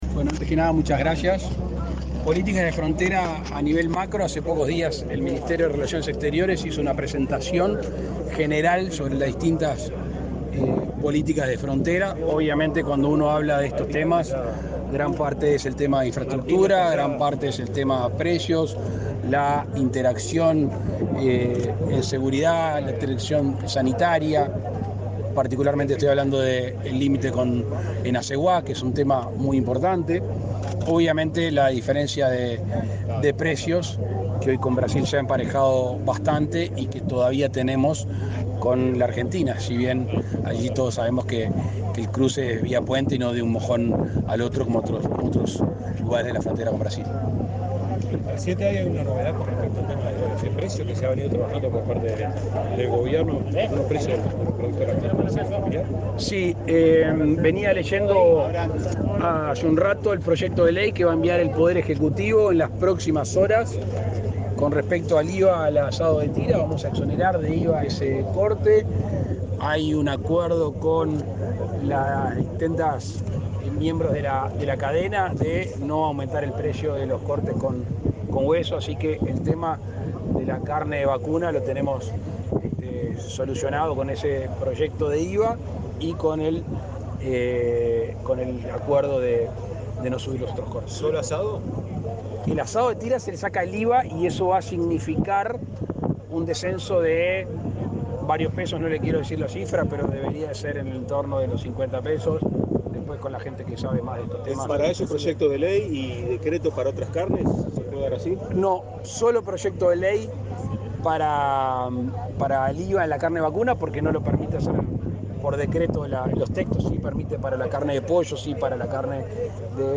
Declaraciones de prensa del presidente de la República, Luis Lacalle Pou
Declaraciones de prensa del presidente de la República, Luis Lacalle Pou 18/03/2022 Compartir Facebook X Copiar enlace WhatsApp LinkedIn Tras participar en el acto de inauguración de la cosecha de arroz en Cerro Largo, este 18 de marzo, el presidente de la República, Luis Lacalle Pou, efectuó declaraciones a la prensa.